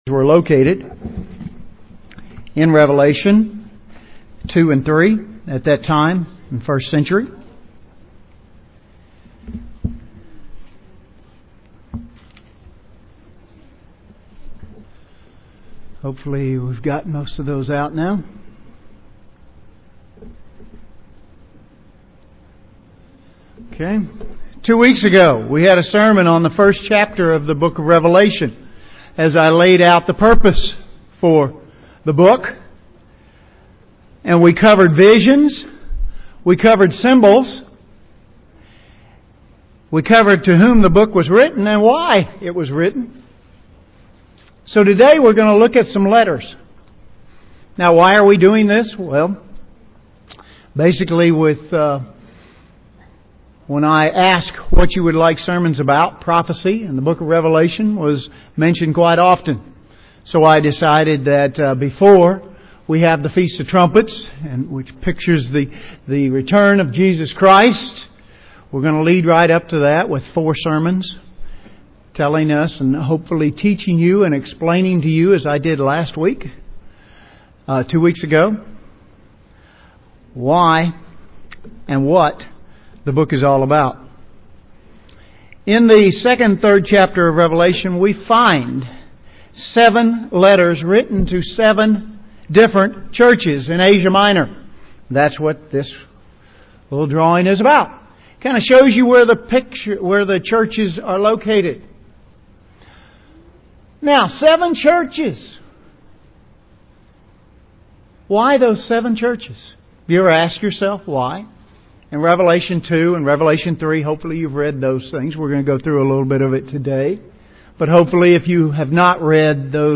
An in-depth look at the seven Churches to whom letters were written UCG Sermon Transcript This transcript was generated by AI and may contain errors.